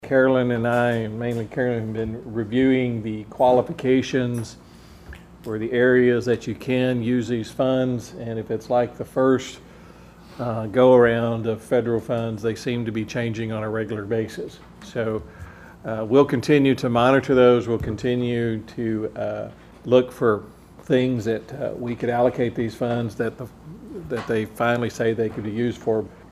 City Manager James Fisher said city staff are still reviewing the various conditions of what the funding can be used for.